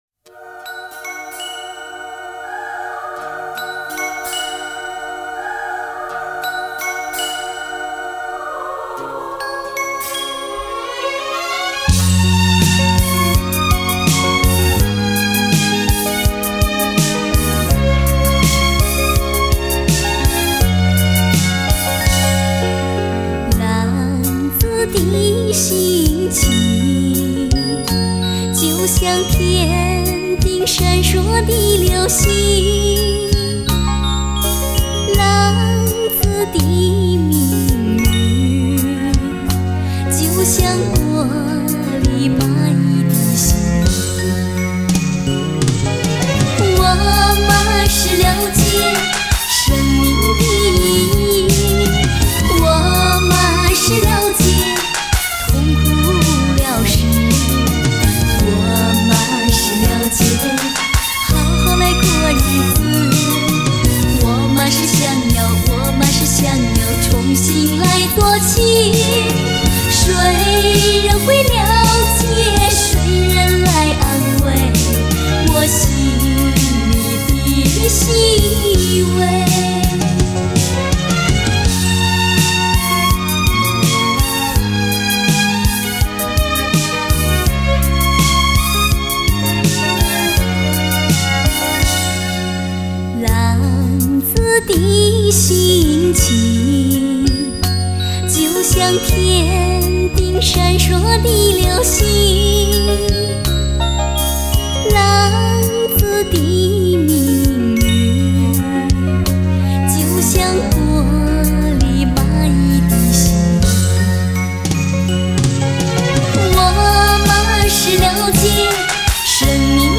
经过美国SRS实验室的SRS音频技术处理，双声道实现虚拟环绕声效果
动态更突出，低频更扎实，高频更宽广。